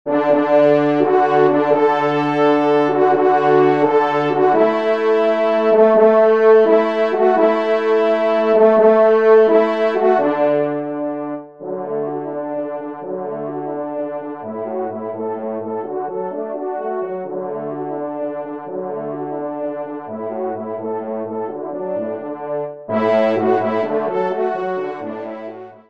3e Trompe